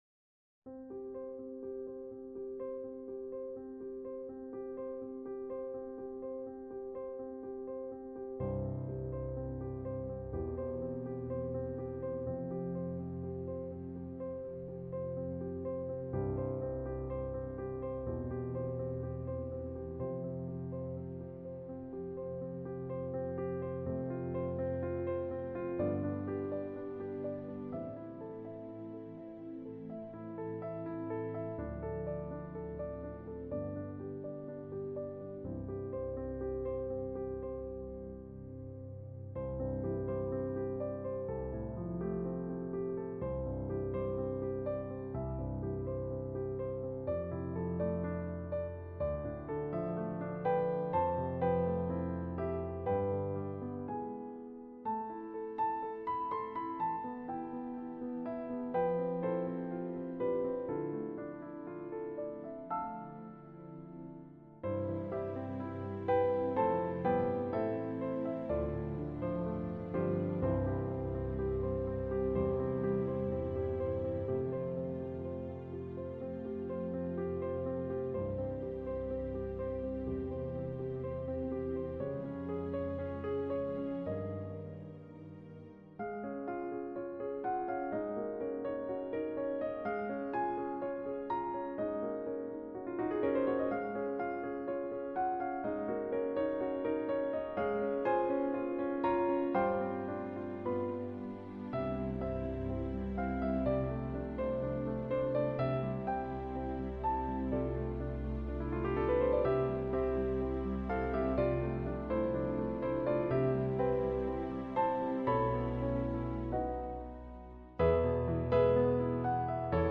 A service for 7th March 2021